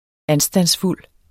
Udtale [ ˈansdans- ]